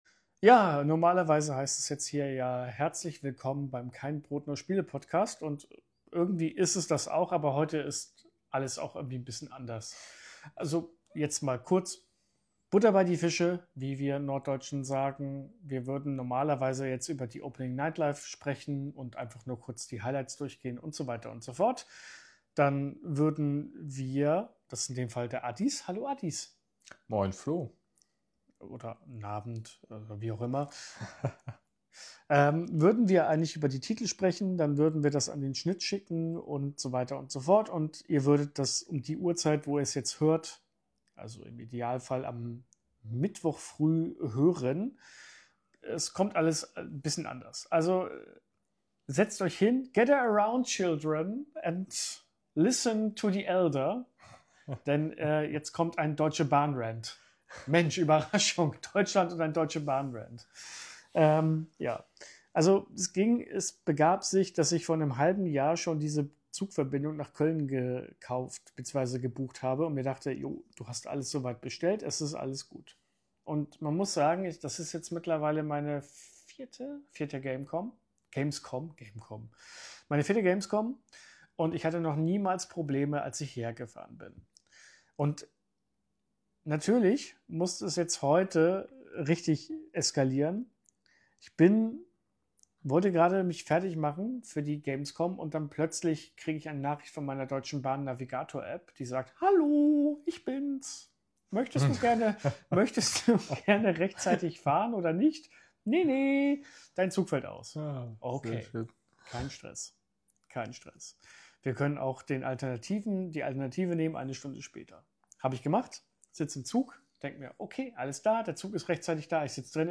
Da ist sie, die erste Folge der Gamescom-Woche und natürlich geht wieder einiges schief und nicht so wie geplant* . Und weil dem so ist, bekommt ihr hier den allerersten Rohschnitt in der kurzen Geschichte des Kein Brot, nur Spiele-Podcasts - völlig ungefiltert, ungeschönt und ungeschminkt.